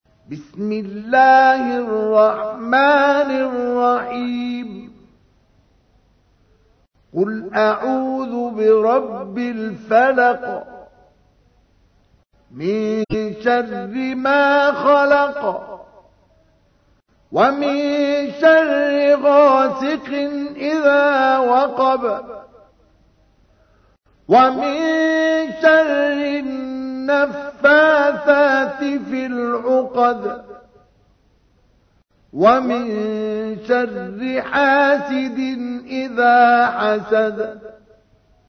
تحميل : 113. سورة الفلق / القارئ مصطفى اسماعيل / القرآن الكريم / موقع يا حسين